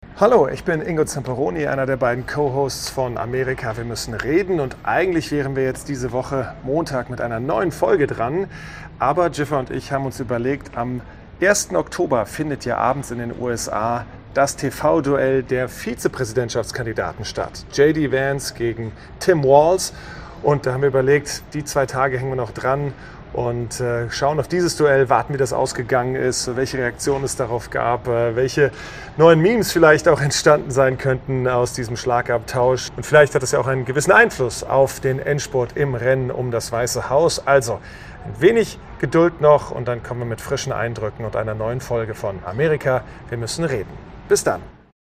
nach Minneapolis vom Flughafen gemeldet.